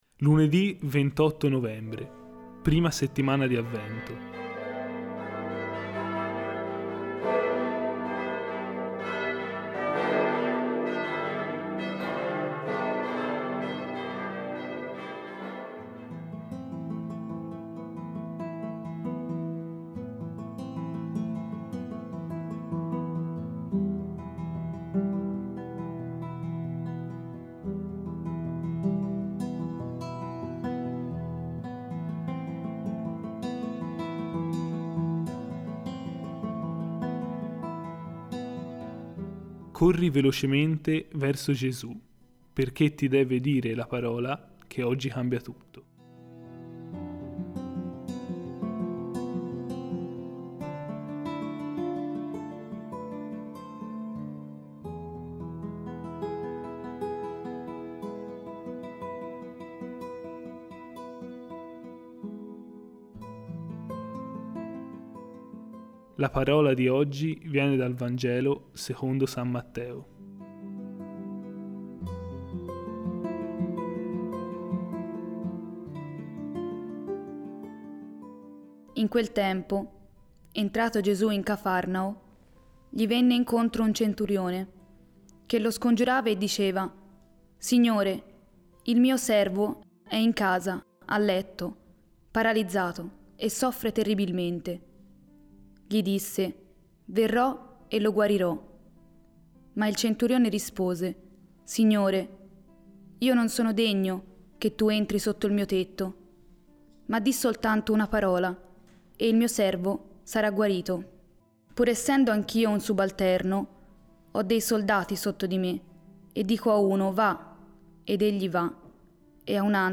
Commento